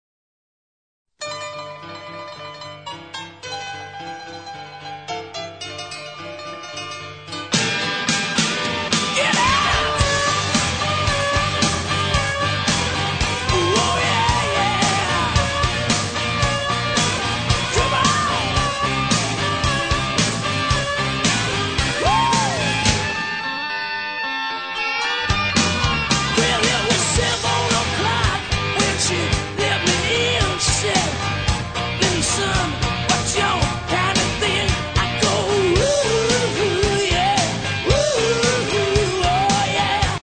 This album is a bar classic. The rolling piano intro